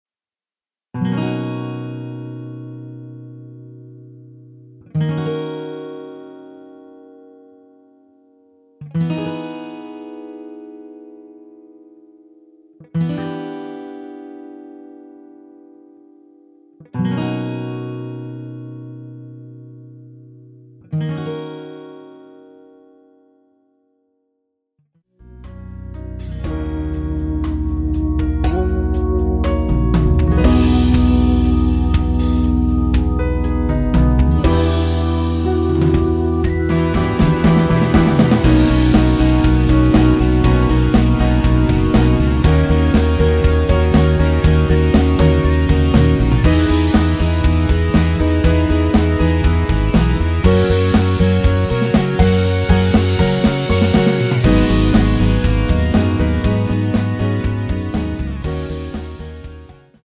멜로디 MR이란
앞부분30초, 뒷부분30초씩 편집해서 올려 드리고 있습니다.
중간에 음이 끈어지고 다시 나오는 이유는